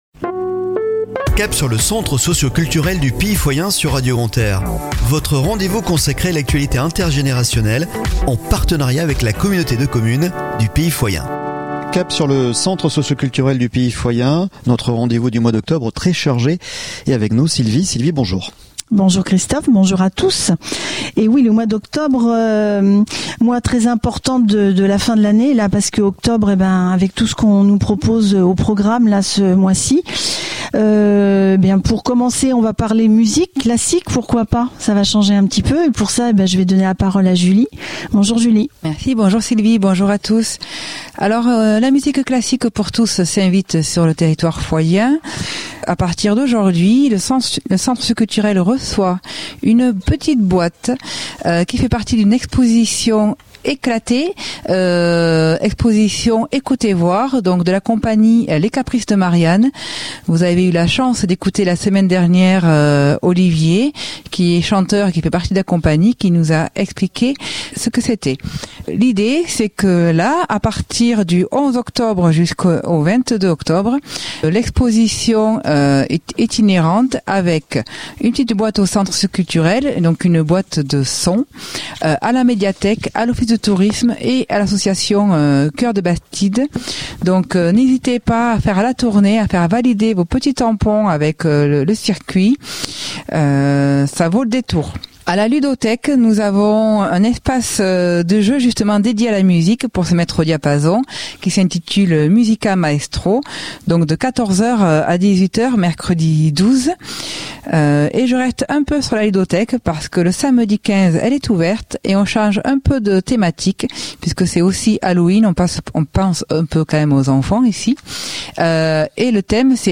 "Cap sur le Centre Socioculturel du Pays Foyen" un rendez-vous consacré à toute l'actualité inter générationnelle et les temps forts proposés sur les 20 communes du Pays Foyen par le Centre Socioculturel. Une chronique animée et préparée par